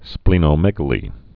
(splēnō-mĕgə-lē, splĕnō-)